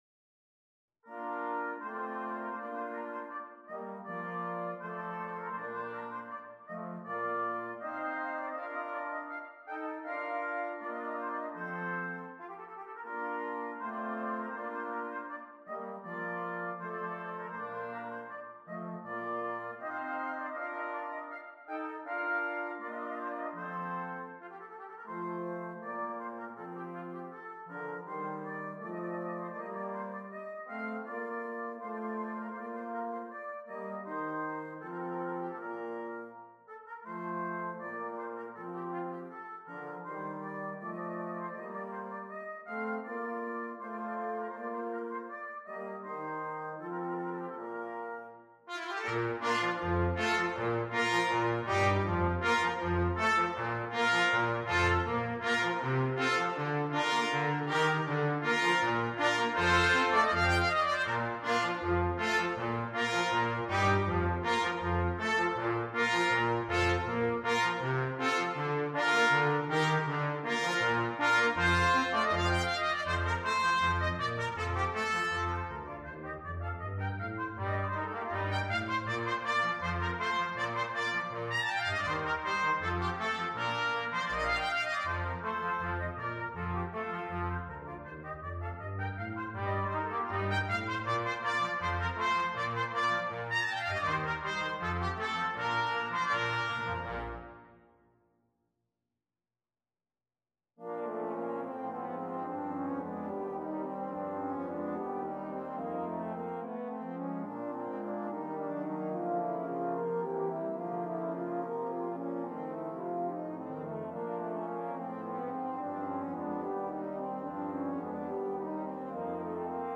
2. Brass Ensemble
10 brass players
without solo instrument
Classical
Part 5: F Horn
Part 10: Tuba – Bass clef